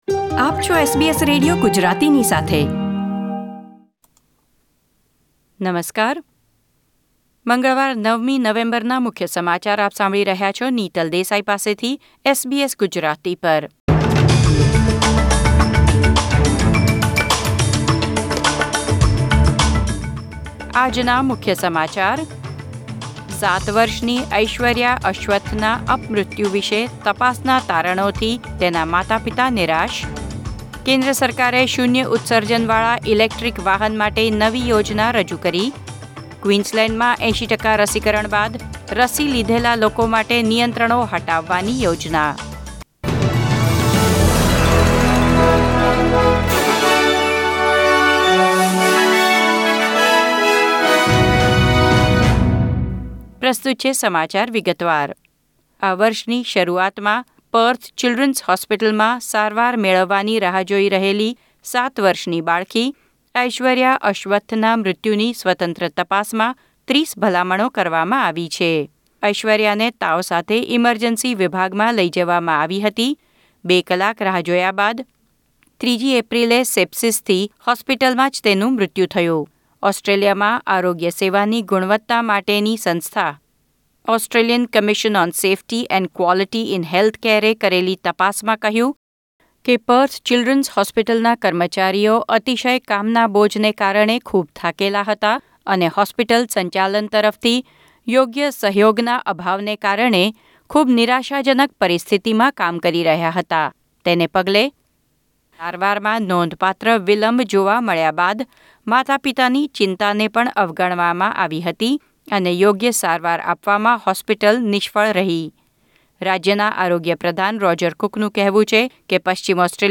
SBS Gujarati News Bulletin 9 November 2021